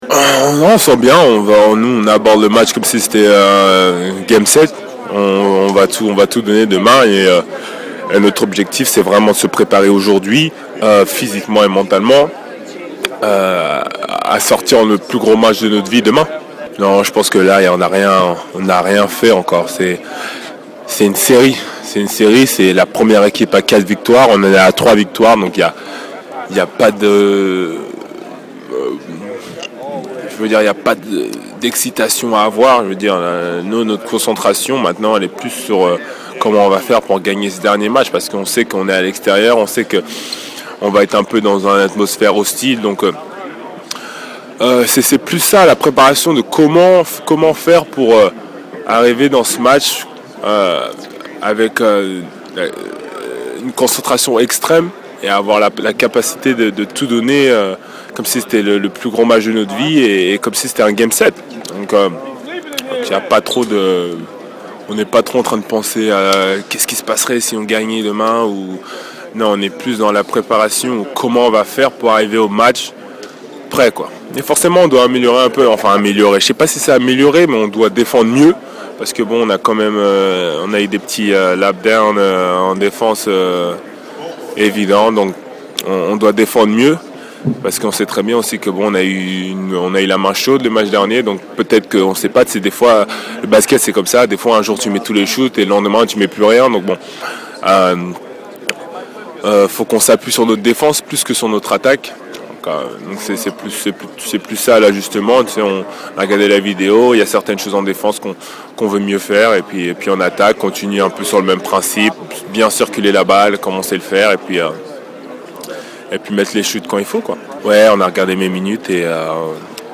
Interview Ian Mahinmi : “on doit mieux défendre”